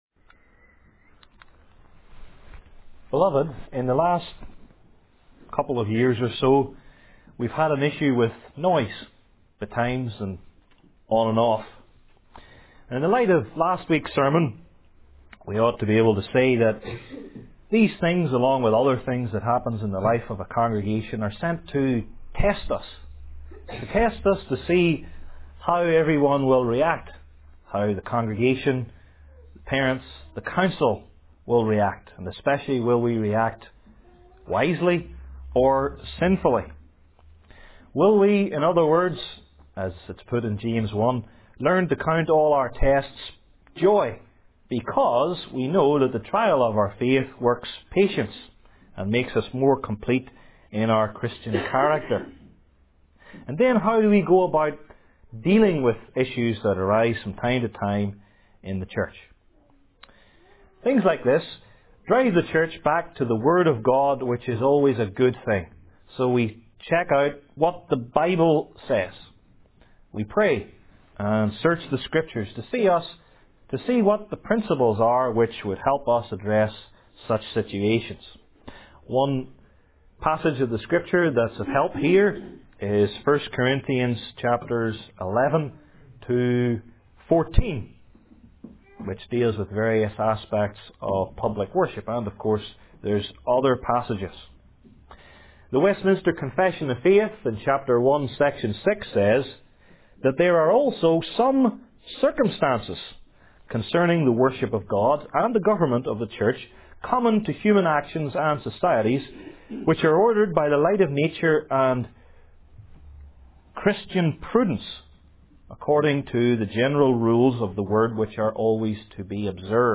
I Corinthians 14:40 Service Type: New Testament Individual Sermons I. The Principles II.